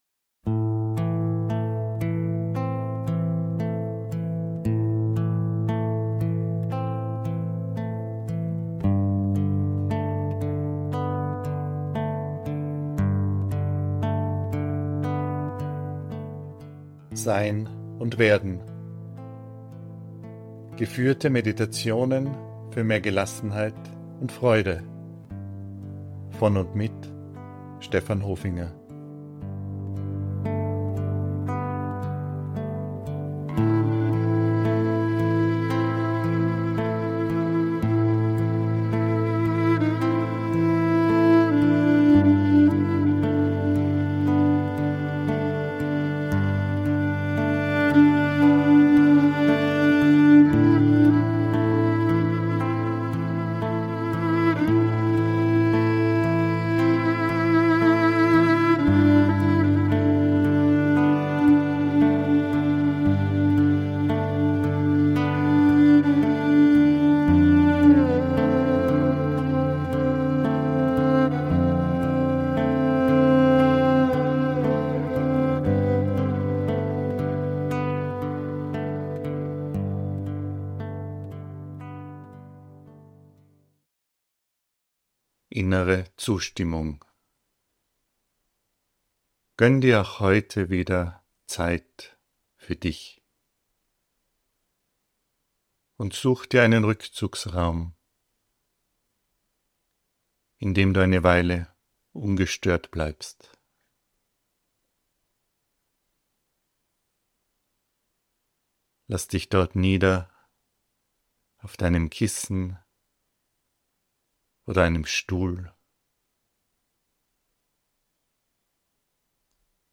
Schön, dass du dich mit dieser Meditation darauf einlässt!